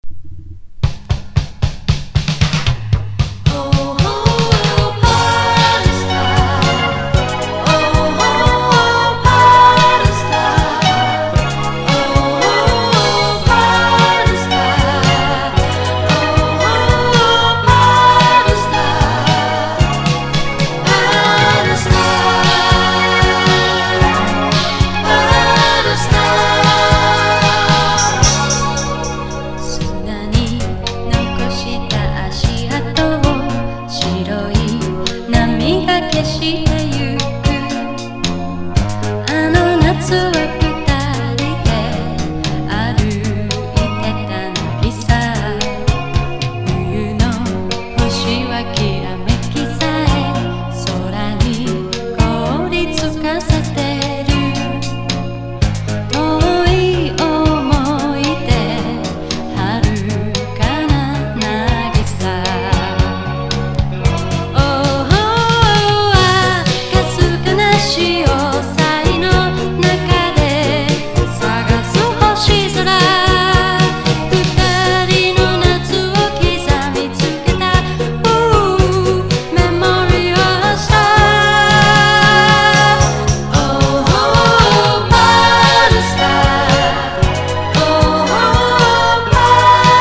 彼女のクールなヴォーカルが素敵&シンセポップな81年作品!
CITY POP / AOR